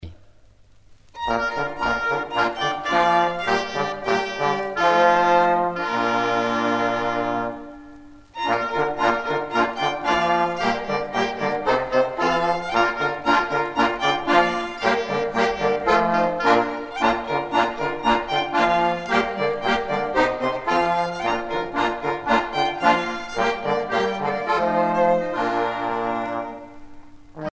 gestuel : danse
Pièce musicale éditée